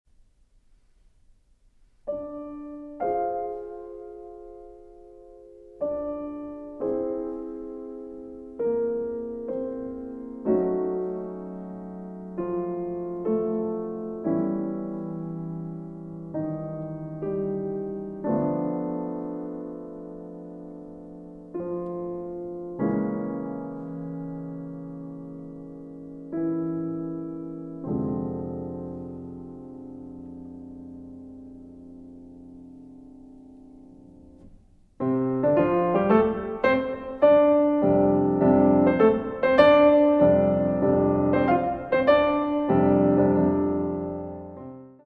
Adagio assai